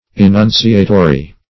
Search Result for " enunciatory" : The Collaborative International Dictionary of English v.0.48: Enunciatory \E*nun"ci*a*to*ry\, a. Pertaining to, or containing, enunciation or utterance.